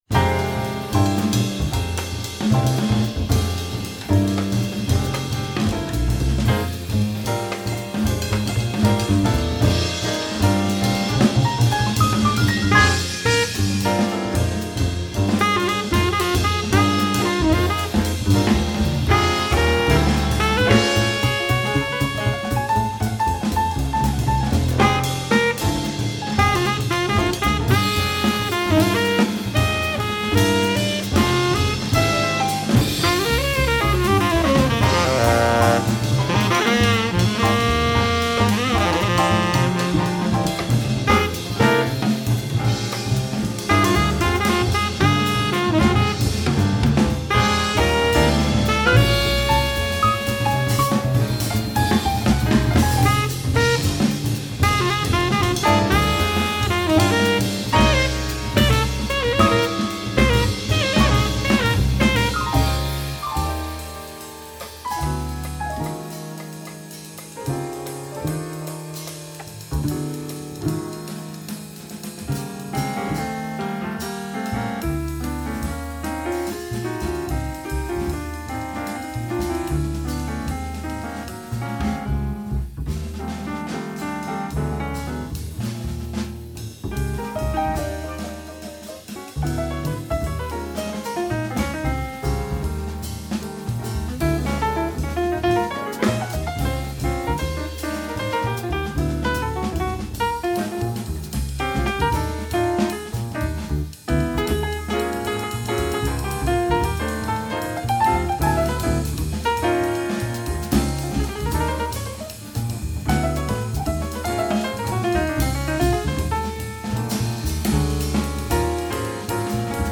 bass
sax
drums
piano)2021